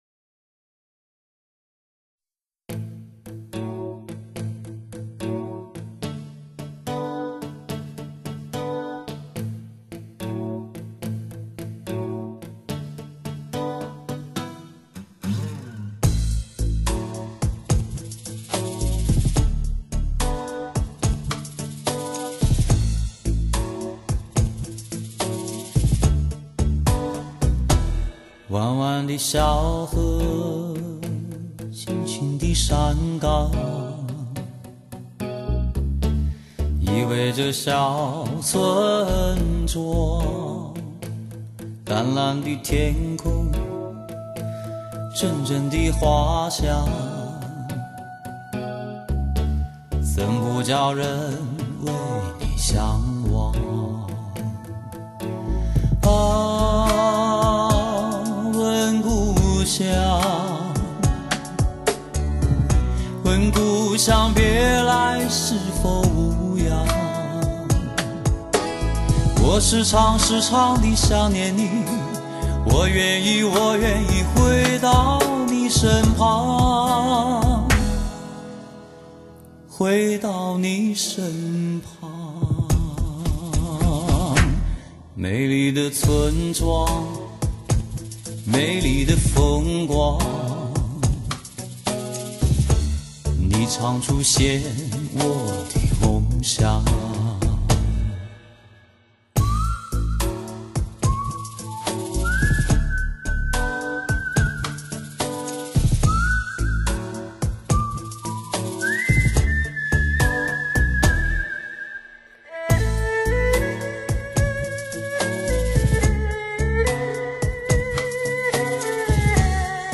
歌魔般纯美男声、浪人歌者之醇厚宝典
大师级典范混录 声声迷离沉醉 首首鲜活动听
顶级阵容联手打造 民谣唱风示范天碟
跃然纸上的灵气、质朴的唱风、顶级的录音制作。